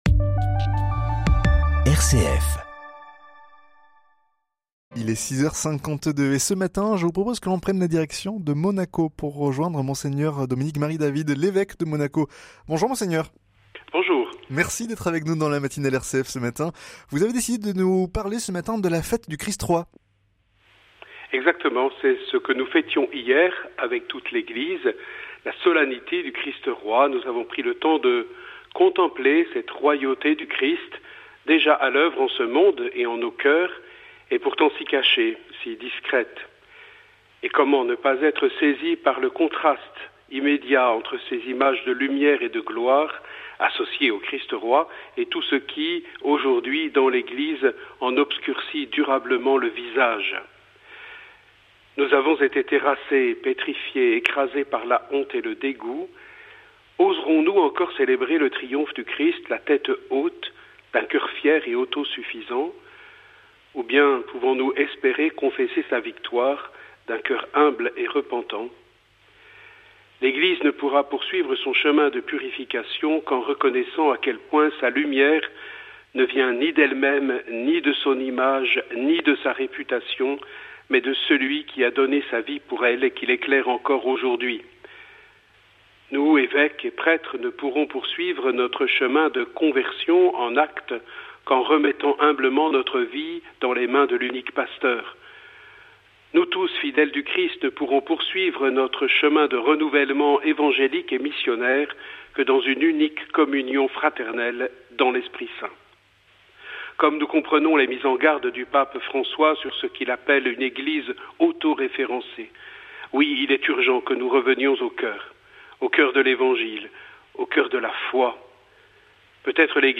Fichier audio ci-dessous au format mp3 Documents Interview de Mgr DAVID pour la radio RCF